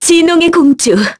Lewsia_B-Vox_Skill7-1_kr.wav